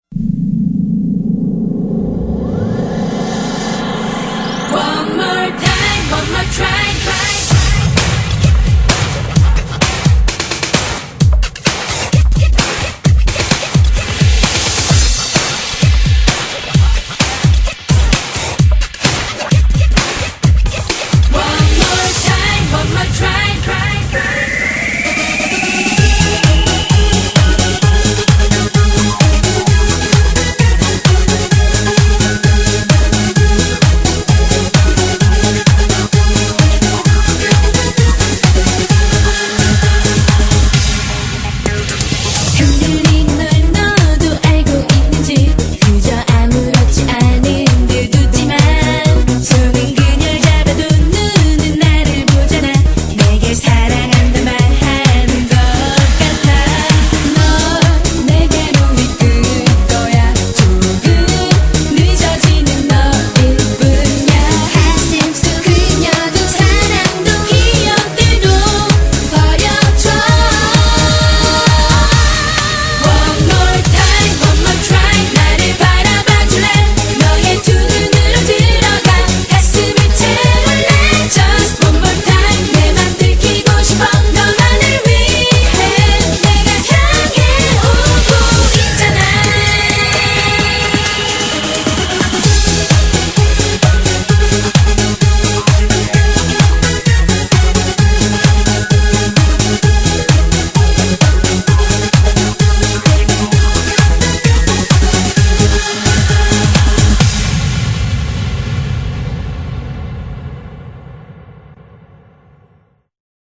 BPM129--1
Audio QualityPerfect (High Quality)